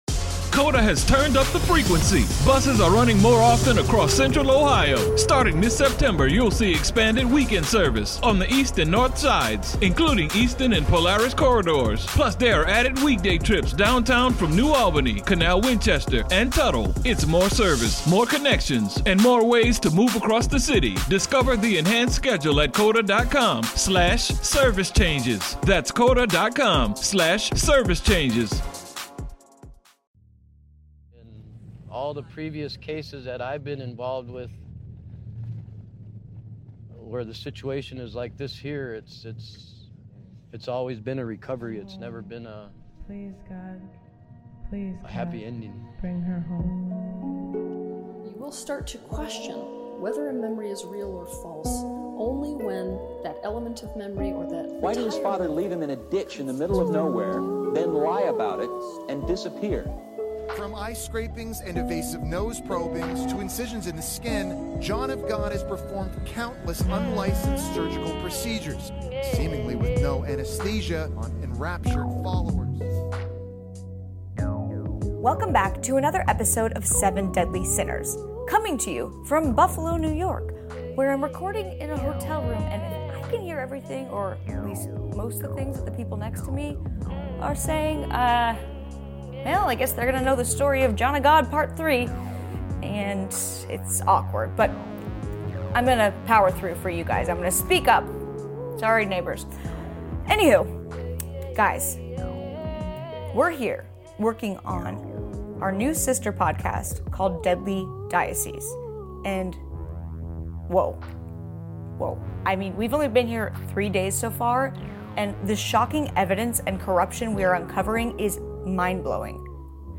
Recorded live from Buffalo